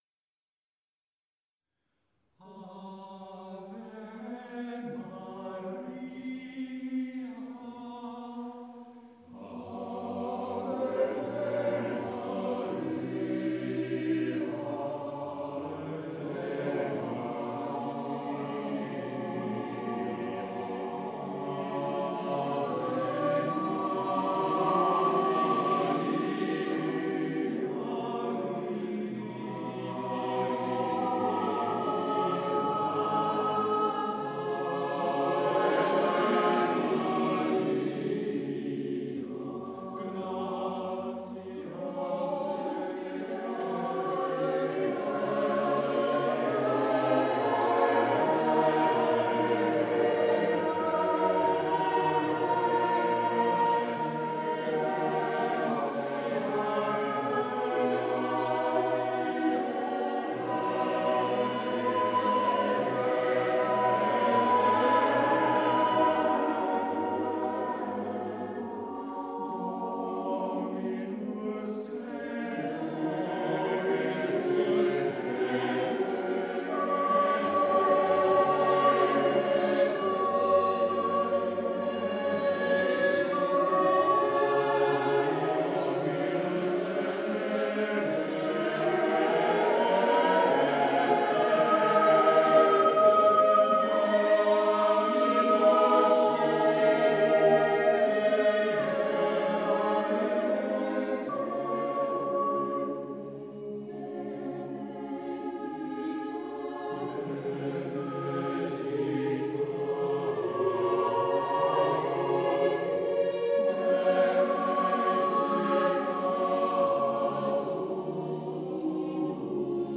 The Choir of Boxgrove Priory
with The Boxgrove Consort of Viols
Recorded live in Boxgrove Priory on the evening of 25th June 2013